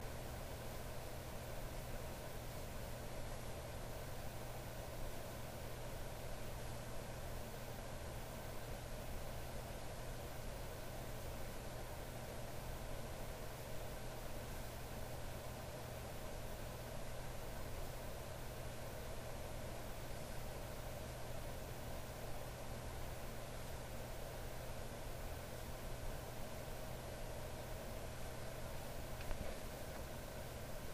Field Recording 1
Location: Dorm Room Sounds Heard: Heater blowing, crackling Dorm Heater
Sounds Heard: Heater blowing, crackling